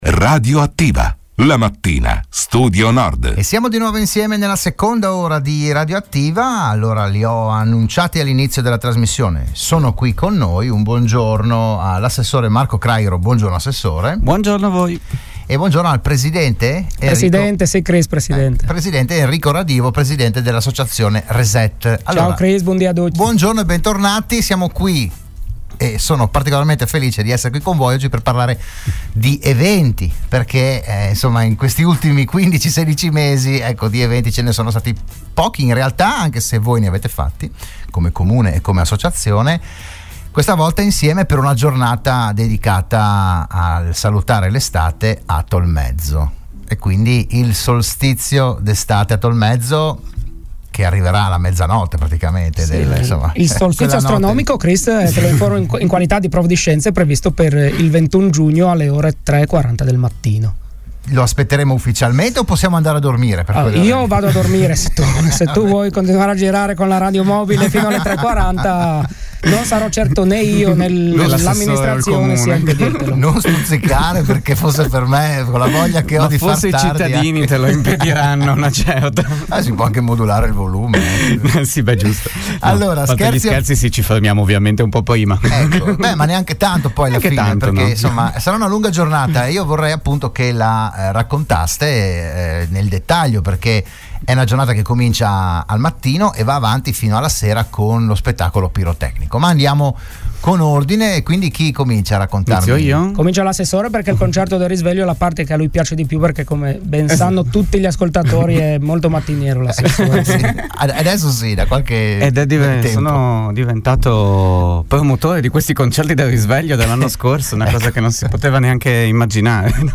Un’intera giornata di eventi, attività e animazione. Il VIDEO e l'AUDIO dell'intervento a RadioAttiva dell'assessore Marco Craighero